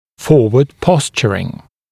[‘fɔːwəd ‘pɔsʧərɪŋ][‘фо:уэд ‘посчэрин]переднее положение (о нижней челюсти), переднее позиционирование